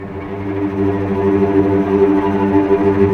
Index of /90_sSampleCDs/Roland LCDP13 String Sections/STR_Vcs Tremolo/STR_Vcs Trem p